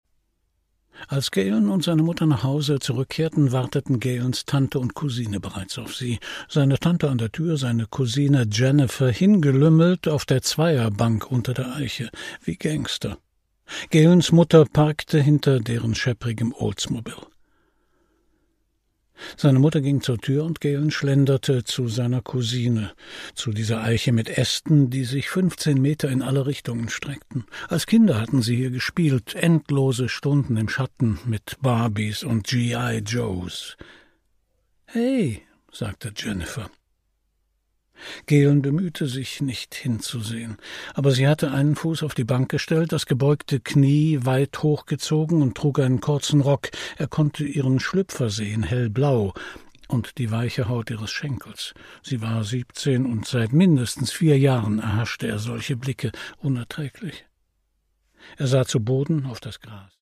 Produkttyp: Hörbuch-Download
Gelesen von: Christian Brückner